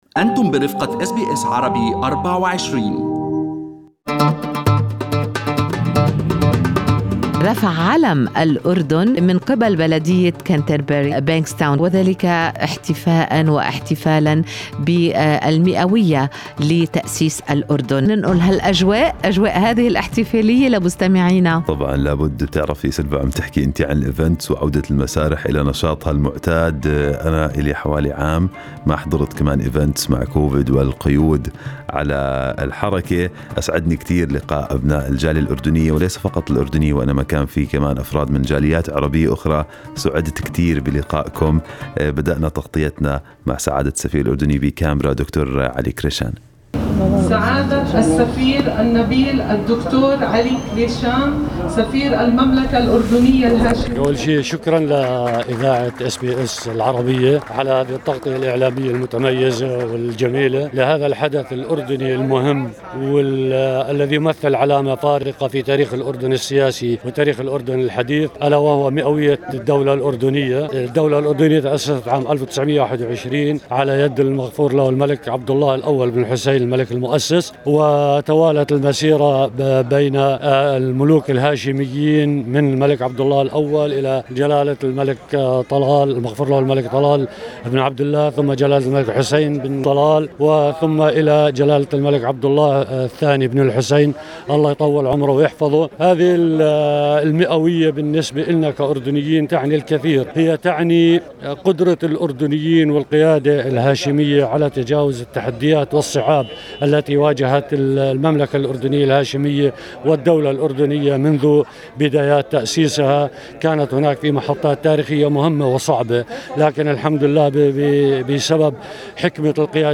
استمعوا إلى تقرير مفصل من الفعالية في الملف الصوتي المرفق بالصورة أعلاه.